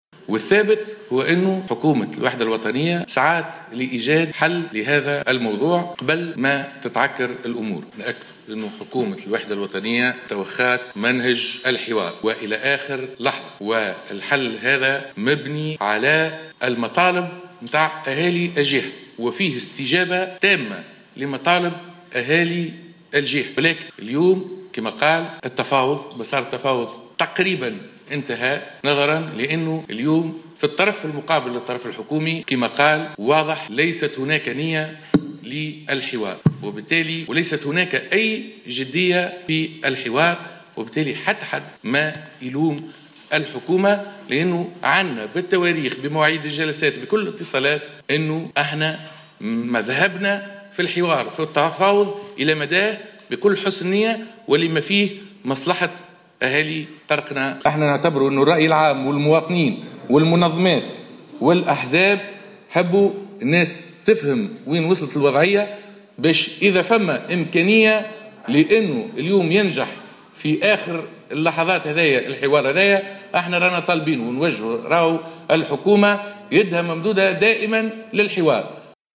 Le porte-parole officiel du gouvernement Iyed Dahmani a déclaré, lors d'un point de presse tenu dans l'après-midi de mercredi, que le gouvernement a usé de toutes les formes de négociation avec la Petrofac concernant sa décision de quitter la Tunisie.